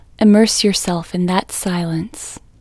LOCATE OUT English Female 22